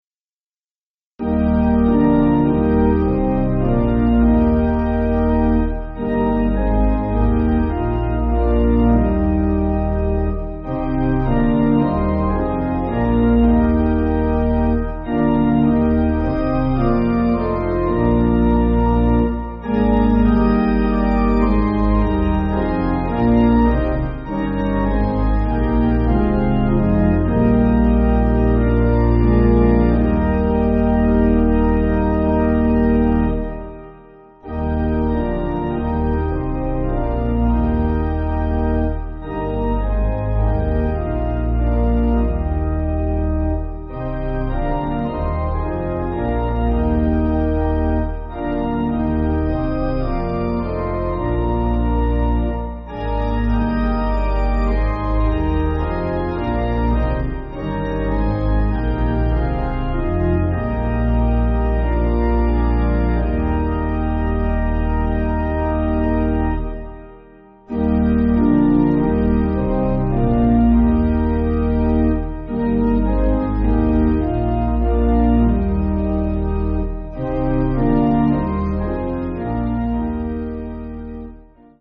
(CM)   6/Eb